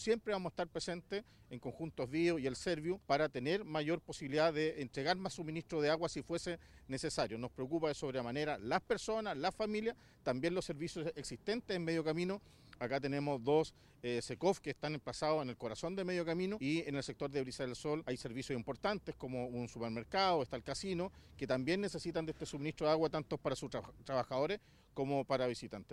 Desde el municipio, el alcalde de Talcahuano, Eduardo Saavedra, indicó que se realizará un monitoreo permanente en terreno durante el desarrollo del corte, además de exigir el cumplimiento de los plazos comprometidos con la comunidad.
cuna-alcalde-talcahuano.mp3